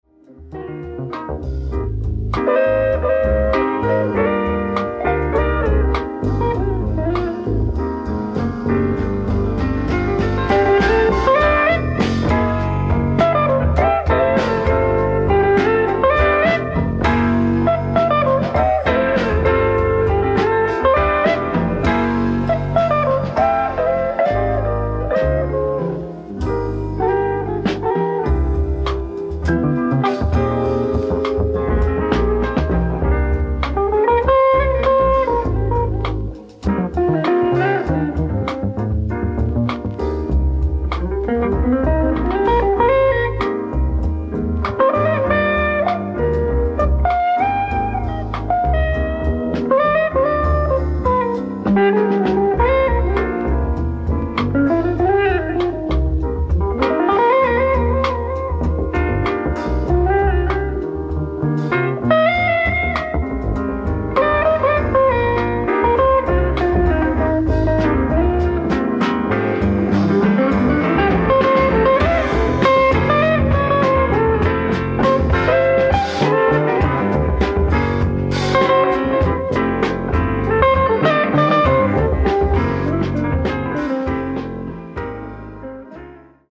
ディスク２：ライブ・アット・ジャズ・ワークショップ、ボストン 09/21/1976
※試聴用に実際より音質を落としています。
Disc 2(Stereo Master)